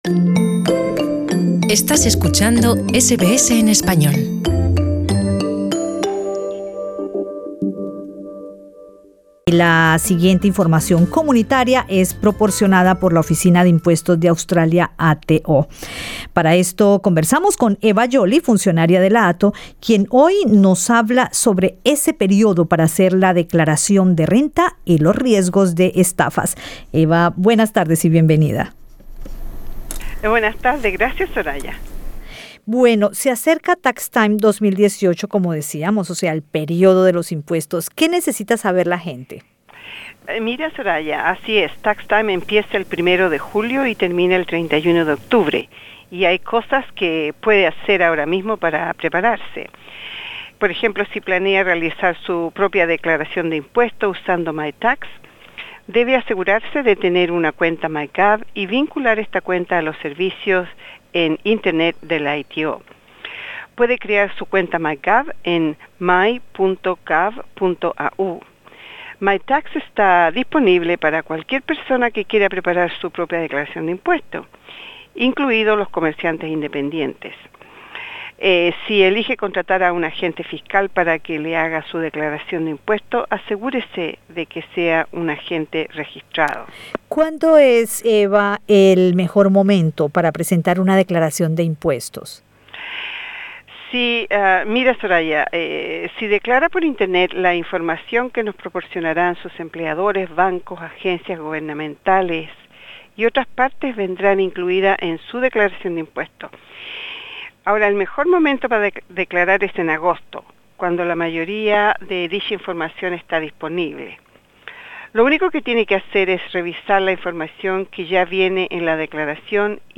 La siguiente información comunitaria es proporcionada por la Oficina de Impuestos de Australia. Entrevista con la funcionaria de la ATO